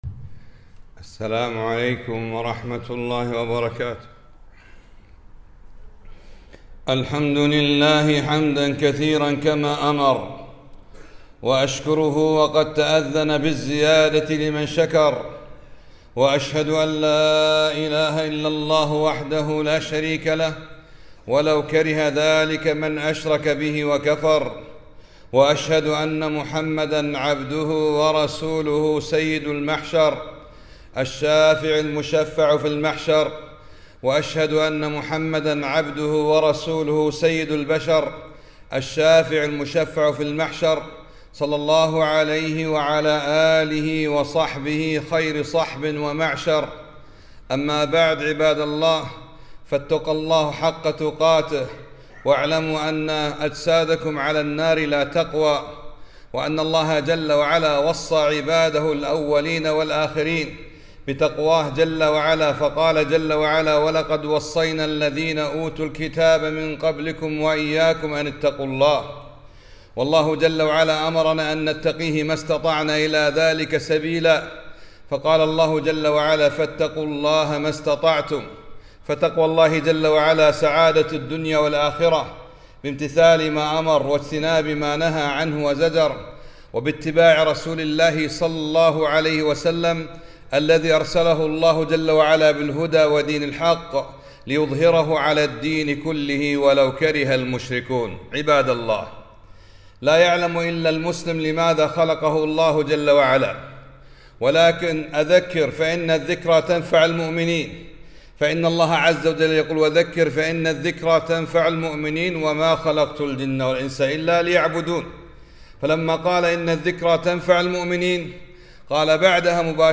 خطبة - لماذا خلقنا الله؟ خلقنا الله عزوجل لثلاث